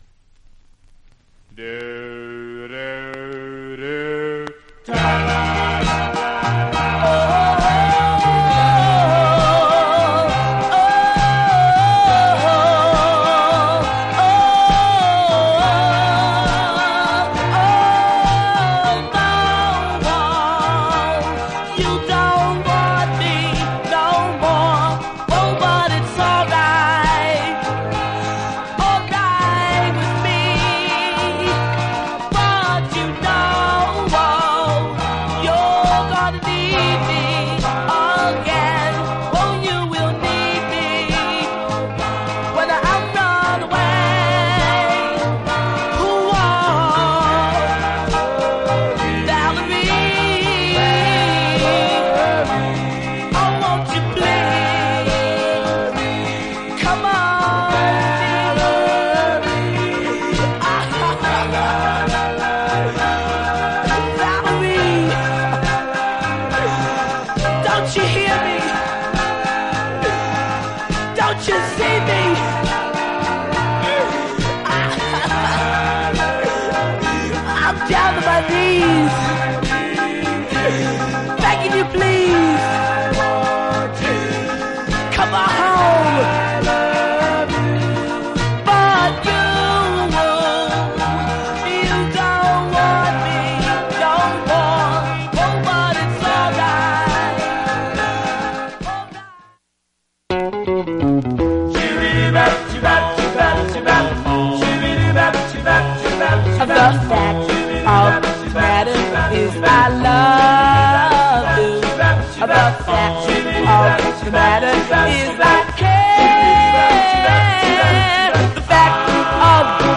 Doowop